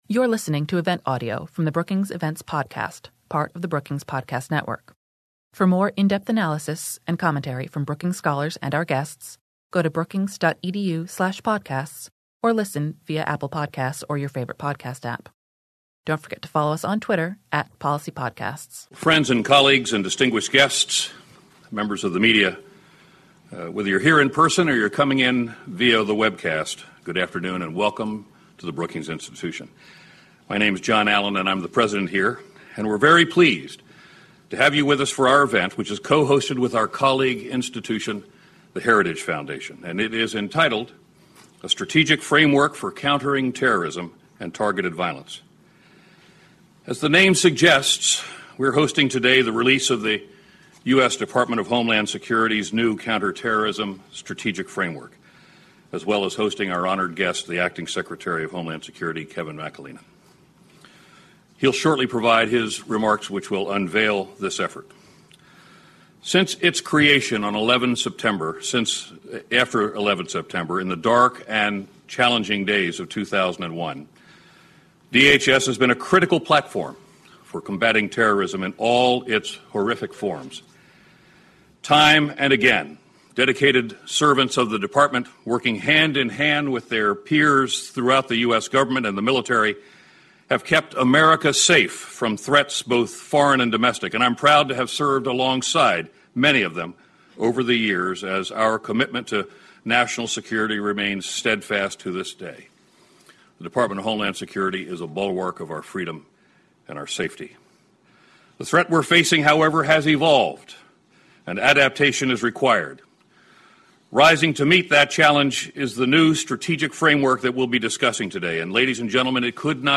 On September 20, Department of Homeland Security Acting Secretary Kevin McAleenan delivered remarks on a new DHS framework on countering domestic terrorism and targeted violence at the Brookings Institution. The event was co-sponsored by Brookings and the Heritage Foundation.